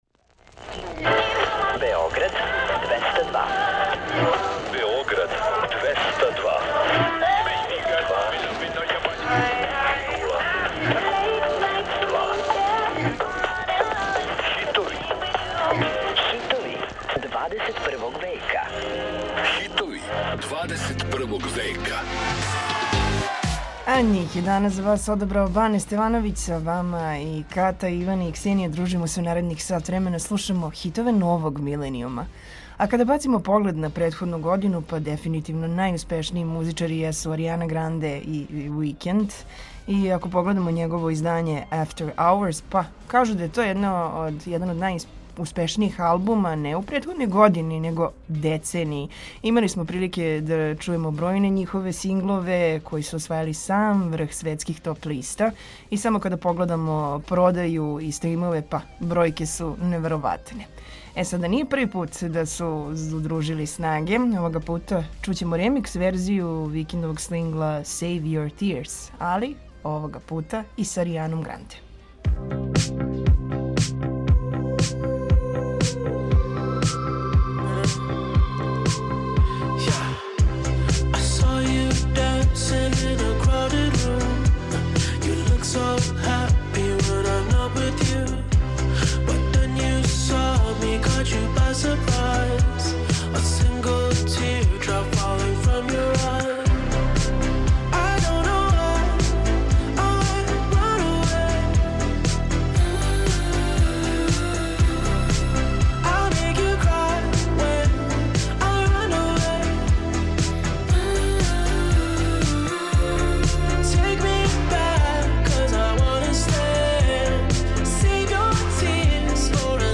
Слушамо хитове новог миленијума, које освајају топ листе и радијске станице широм планете.